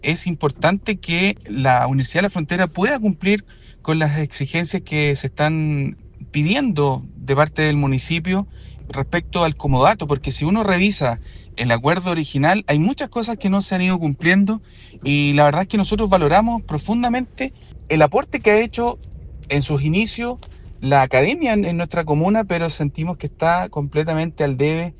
En la misma línea se pronunció su par de Renovación Nacional, Emilio Ulloa, indicando que hay muchos puntos del comodato que la UFRO no cumplió.